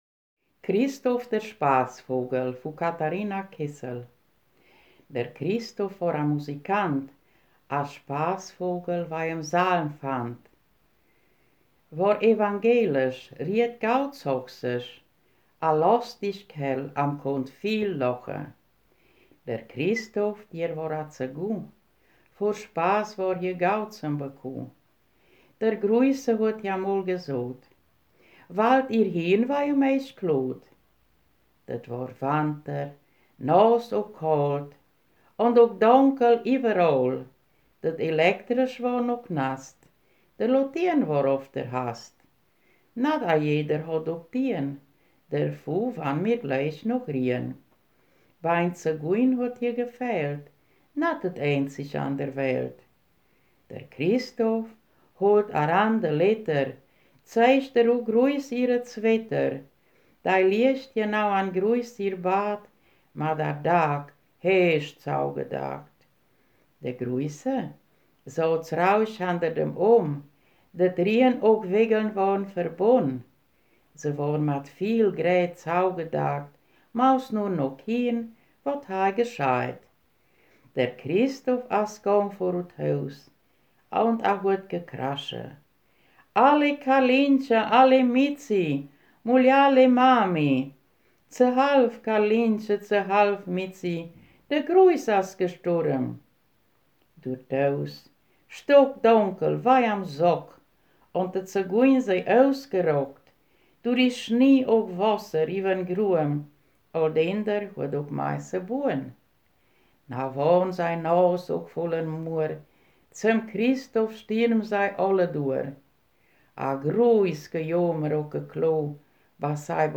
Ortsmundart: Paßbusch